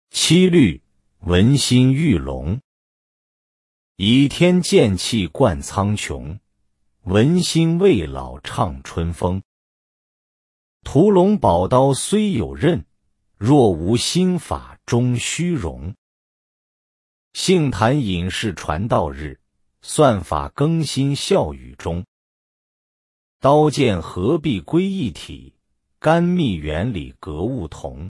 音频朗读